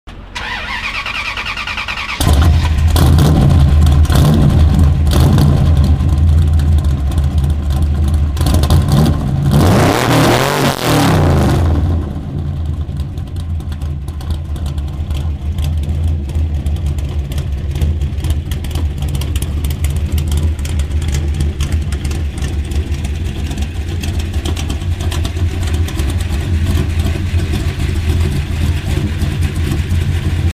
motor-v8-plymouth-barracuda.mp3